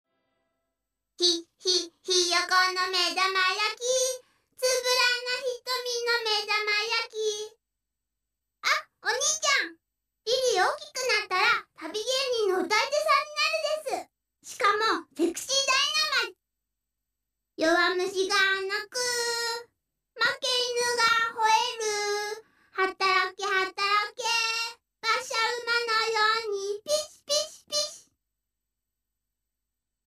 音量は意図的に小さめにしてあります。